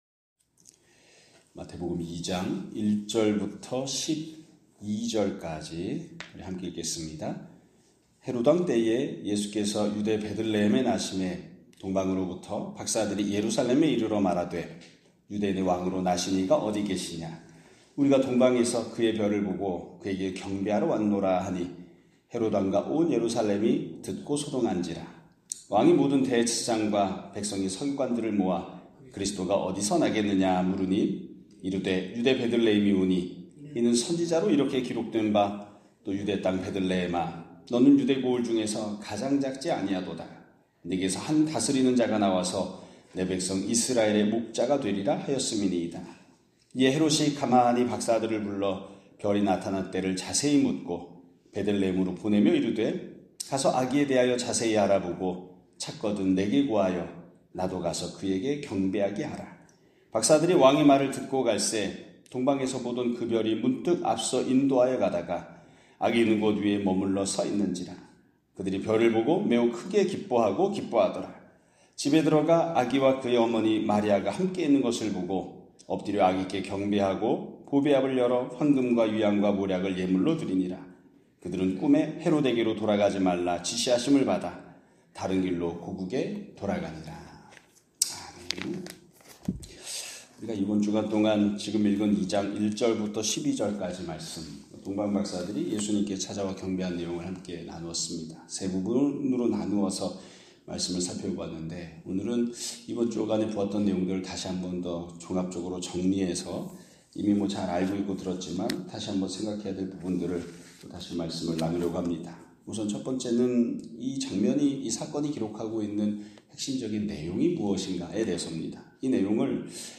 2025년 4월 3일(목 요일) <아침예배> 설교입니다.